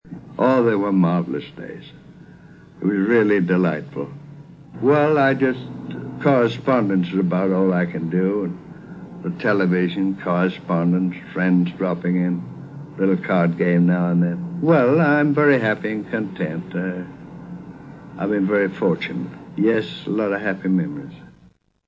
However, he did do some interviews, like the radio interview from 1959, now entitled "The Life and Times of Stan Laurel".